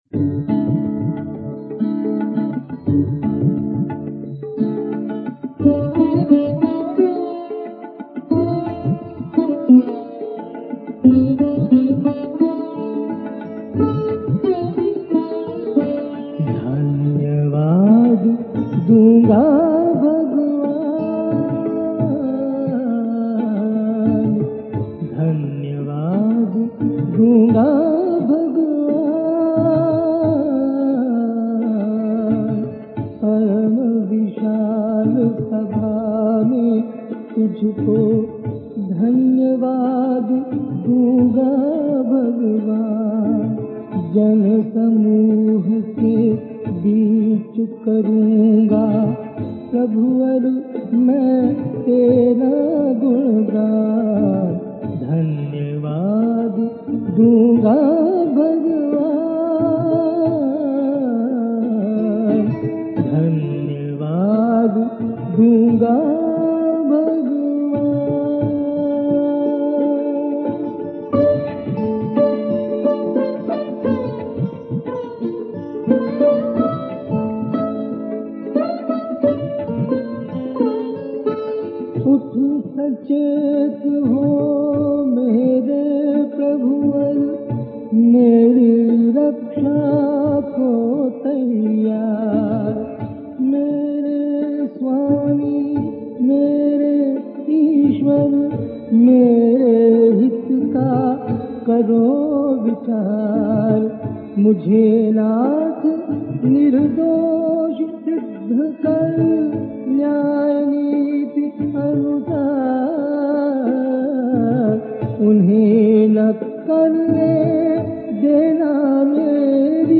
Hymns